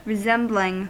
Ääntäminen
Ääntäminen US : IPA : [ɹɪ.ˈzɛm.blɪŋ] Haettu sana löytyi näillä lähdekielillä: englanti Käännöksiä ei löytynyt valitulle kohdekielelle.